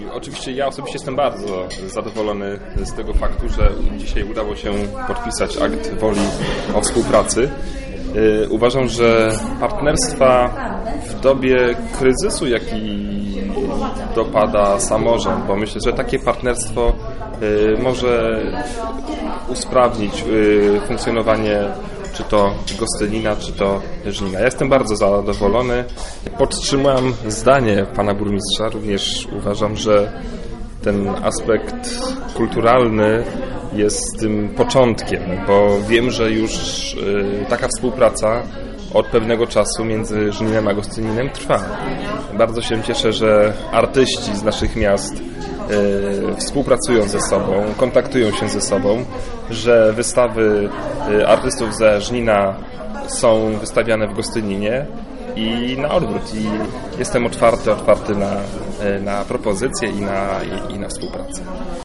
Zadowolenia z podpisania aktu współpracy nie kryje również burmistrz Gostynina Paweł Kalinowski.